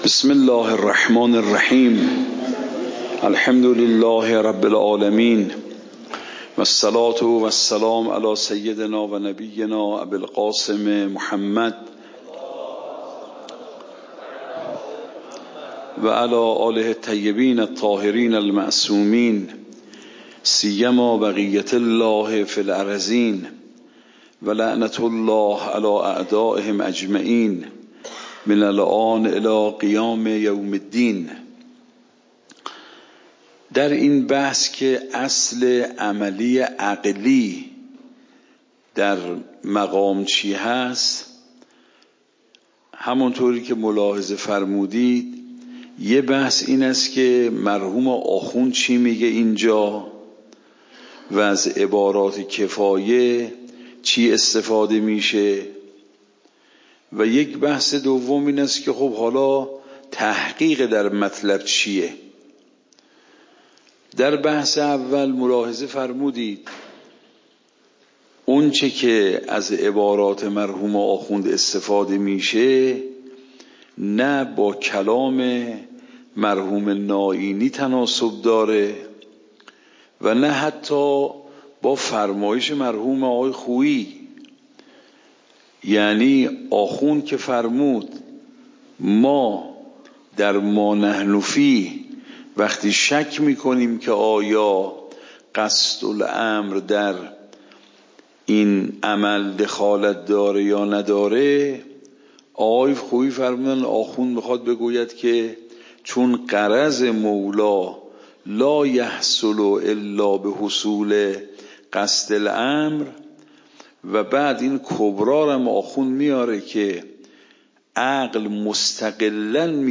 درس بعد تعبدی و توصلی درس قبل تعبدی و توصلی درس بعد درس قبل موضوع: واجب تعبدی و توصلی اصول فقه خارج اصول (دوره دوم) اوامر واجب تعبدی و توصلی تاریخ جلسه : ۱۴۰۴/۲/۸ شماره جلسه : ۸۴ PDF درس صوت درس ۰ ۳۴۸